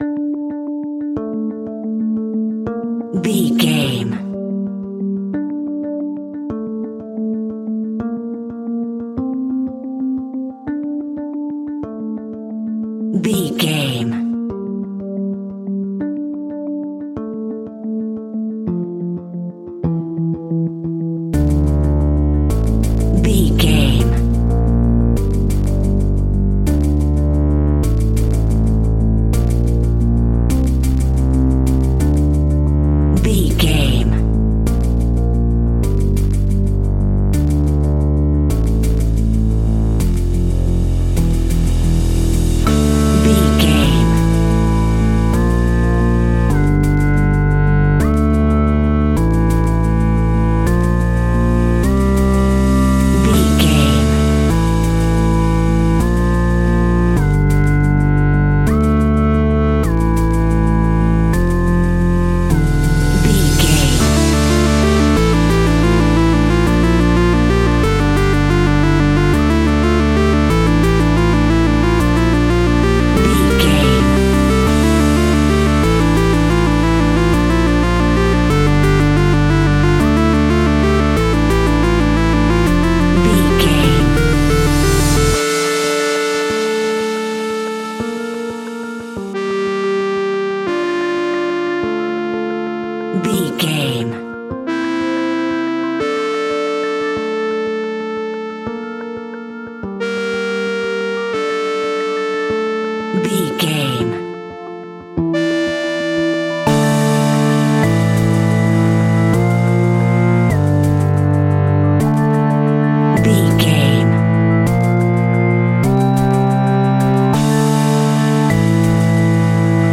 Aeolian/Minor
scary
tension
ominous
dark
haunting
eerie
drums
synthesiser
electronic music
electronic instrumentals